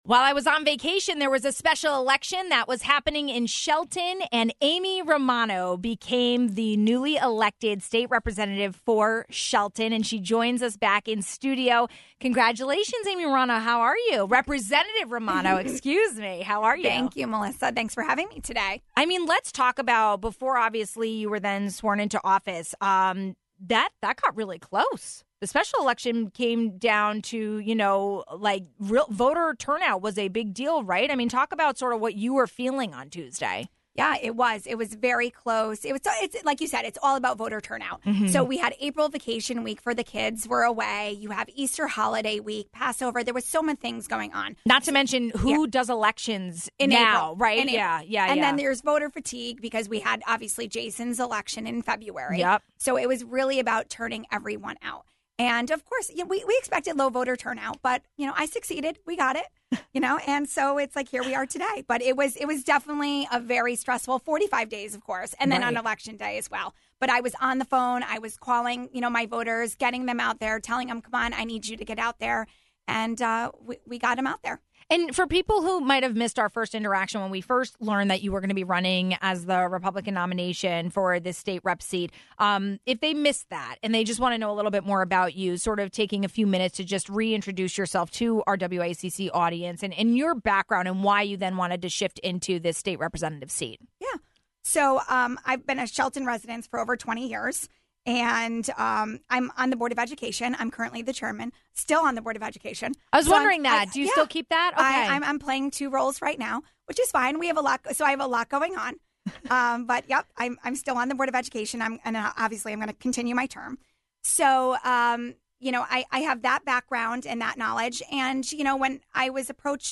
Newly elected State Representative Amy Romano joined us in studio. She represents Shelton and gave us the latest on what’s happening in Hartford during the legislative session.